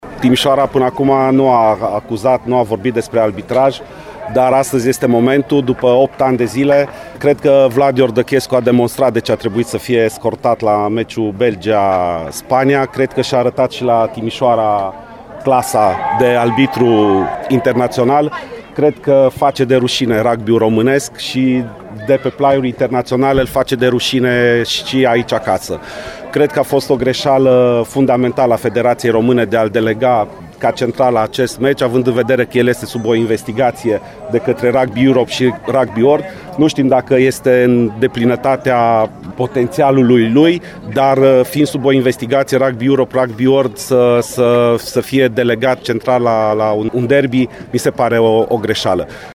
La declarațiile de după meci